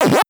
paccoin.wav